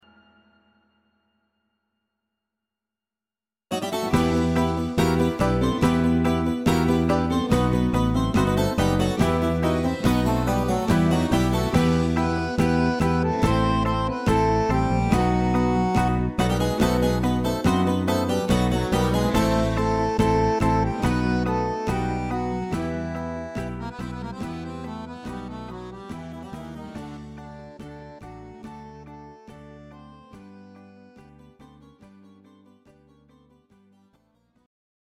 Greek Zeimpekiko Aptaliko